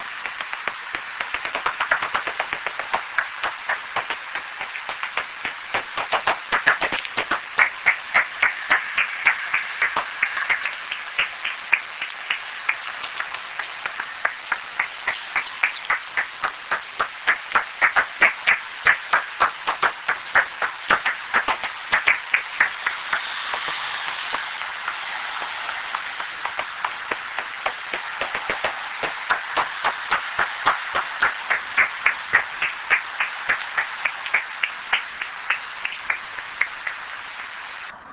En vol, la sérotine commune émet une assez lente succession irrégulière de cris d'écholocation en fréquence modulée aplatie aux alentours de 27 kHz, ce qui donne à cette fréquence au détecteur ultrasonique de chauves-souris des "tchac tchac" sur un rythme irrégulier, comme vous pouvez l'entendre sur ces deux enregistrements:
enregistrement des cris d'écholocation d'une sérotine commune (Pettersson D200 réglé sur 27 kHz - 10/08/2008 - Auderghem, Belgique).